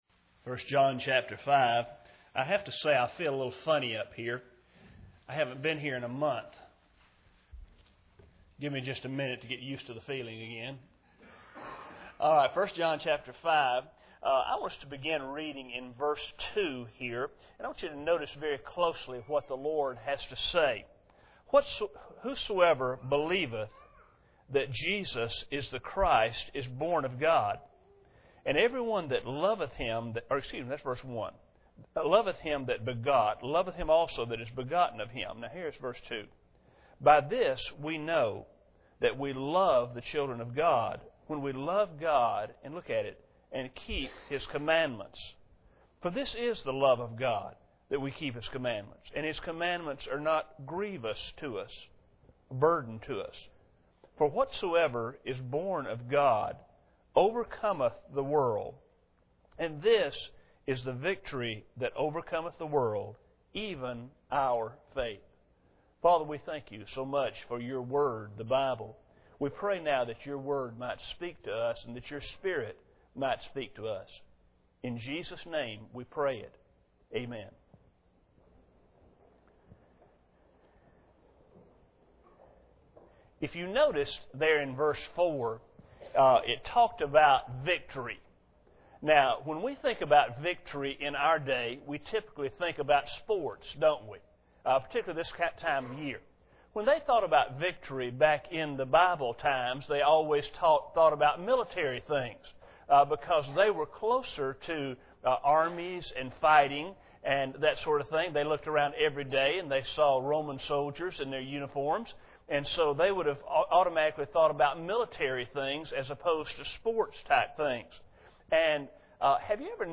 1 John 5:2-4 Service Type: Sunday Morning Bible Text